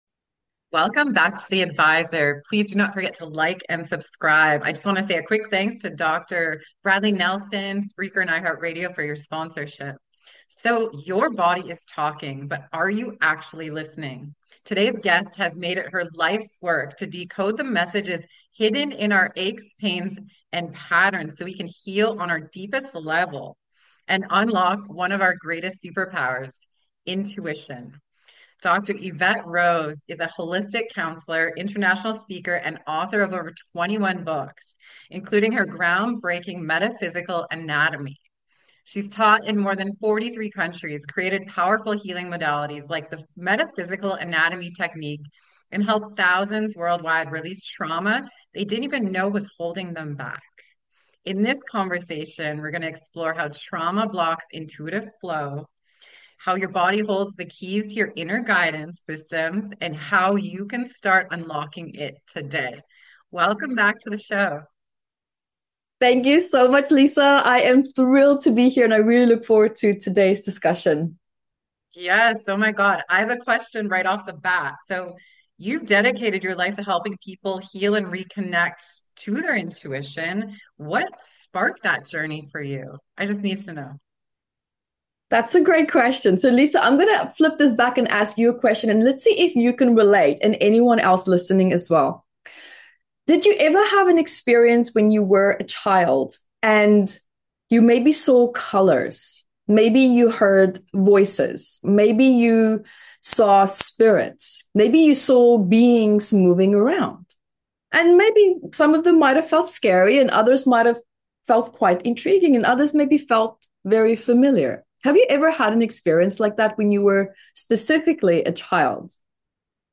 science-meets-spirit conversation on healing blocks and amplifying intuition